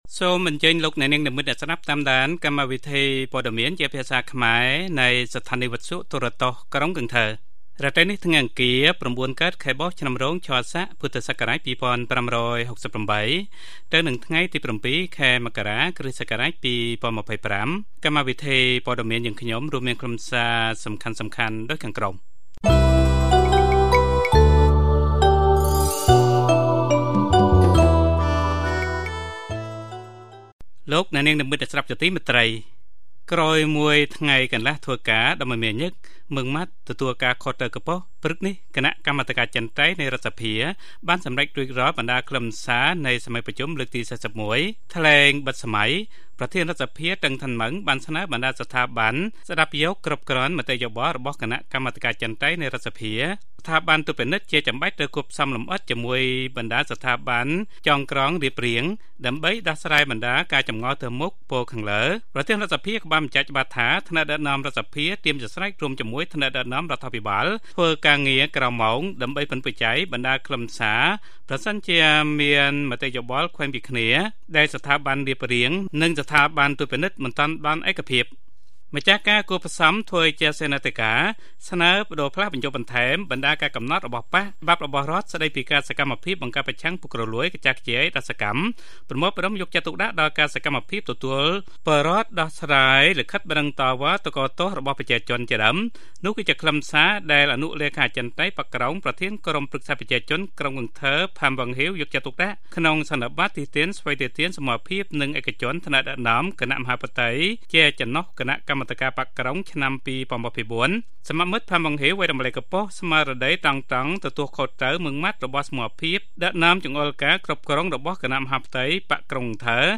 Bản tin tiếng Khmer tối 7/1/2025